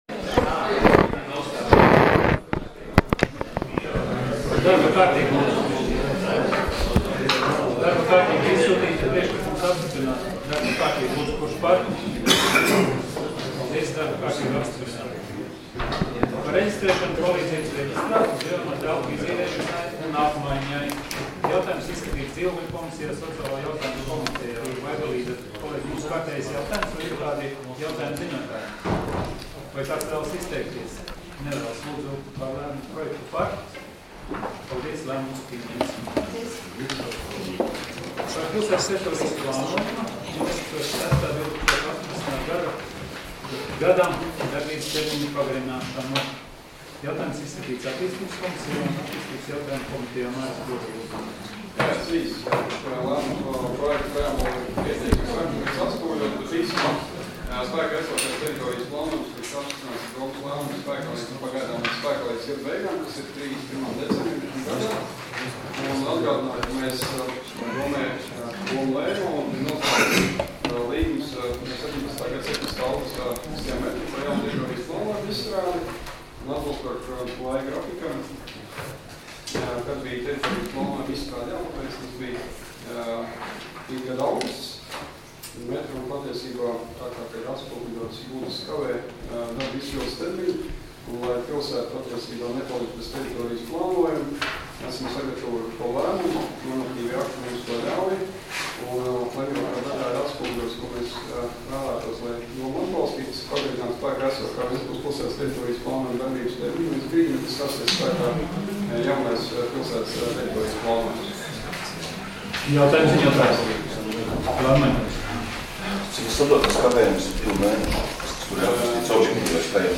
Domes sēdes 31.08.2018. audioieraksts